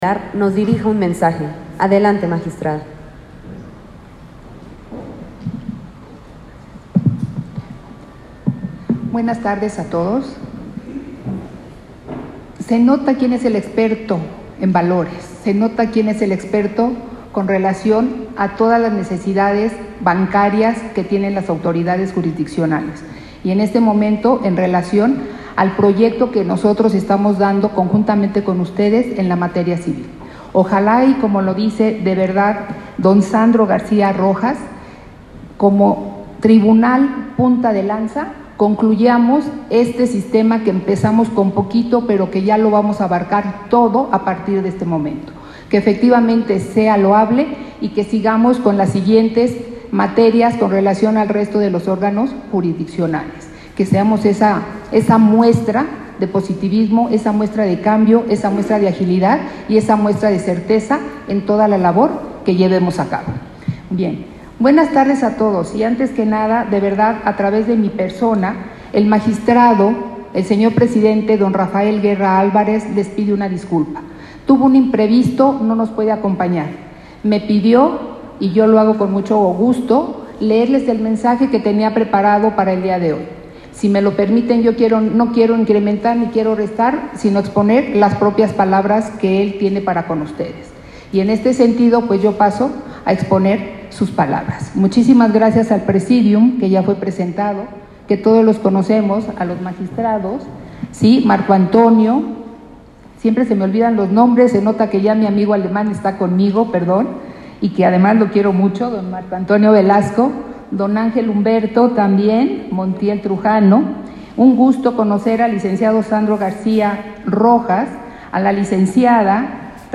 Audio del evento